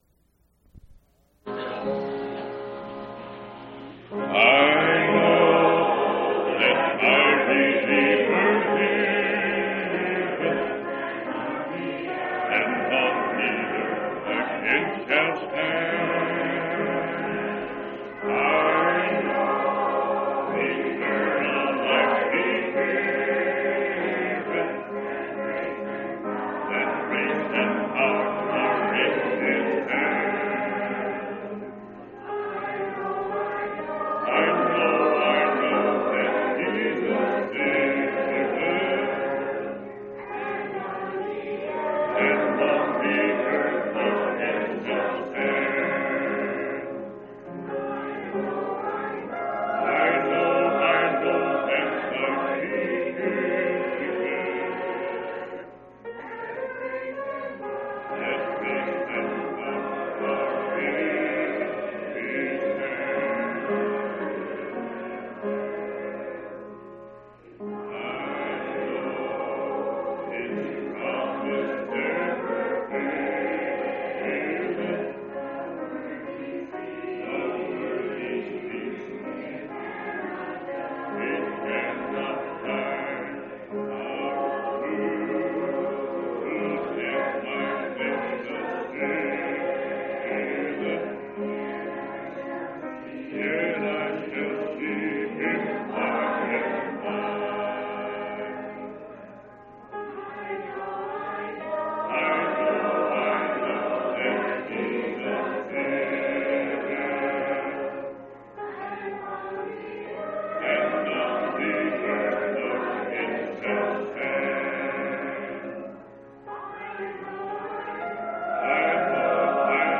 11/25/1989 Location: Phoenix Reunion Event